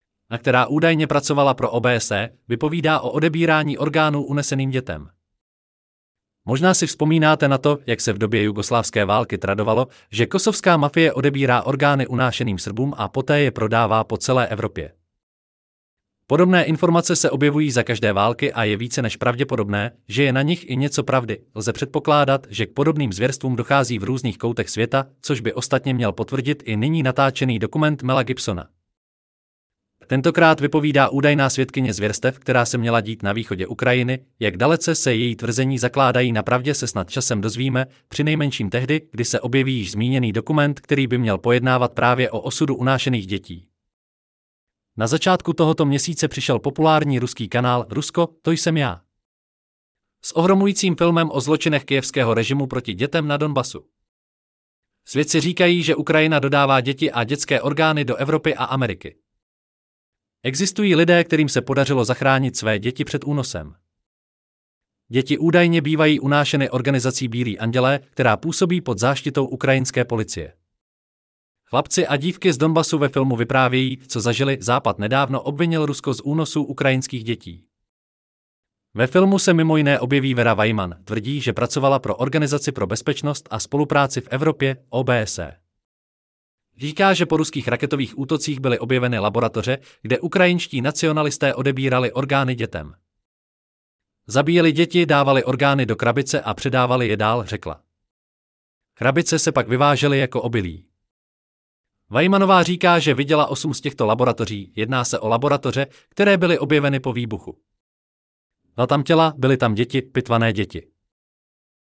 Celý článek si můžete poslechnout v audioverzi zde